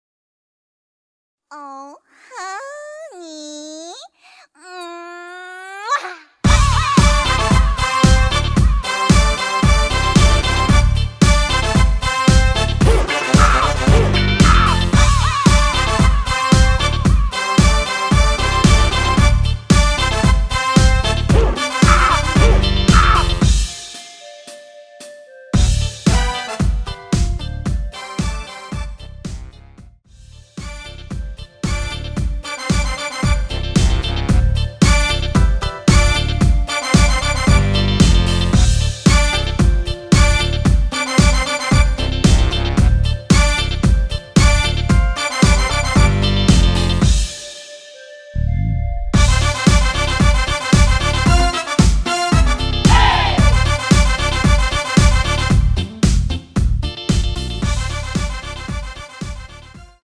원키에서(-1)내린 MR입니다.
Dm
앞부분30초, 뒷부분30초씩 편집해서 올려 드리고 있습니다.
중간에 음이 끈어지고 다시 나오는 이유는